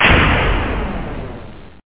plasma_rifle.ogg